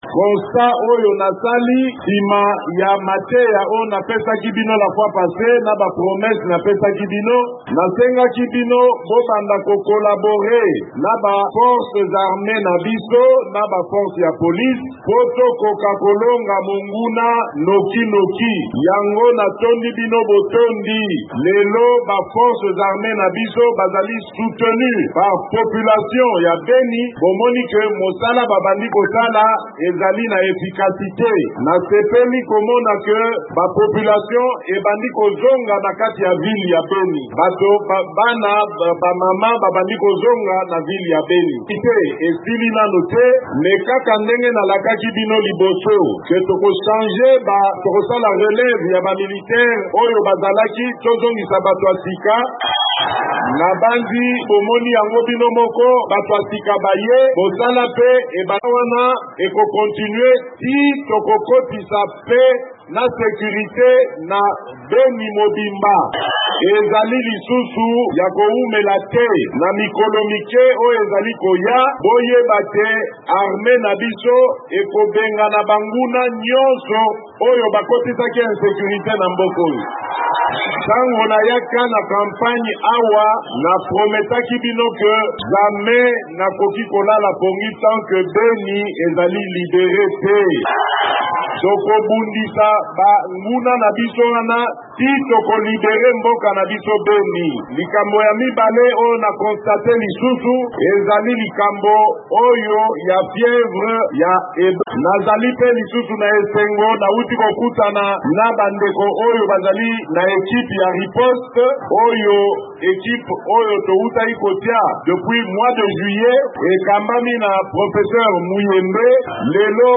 Président Félix Tshisekedi alaki, na meeting na ye liboso bituluku na esika ya 30 juin na Beni, ete mampinga ya FARDC bakobengana batomboki mpe basimba minduki na RDC kala mingi te. Alobi nzela ebandi kobongisama mpo malona mazonga na Beni.